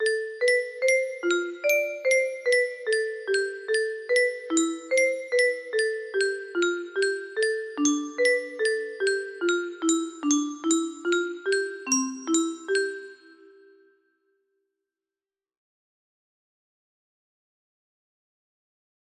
The music box intro